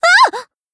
BA_V_Yukari_Battle_Damage_1.ogg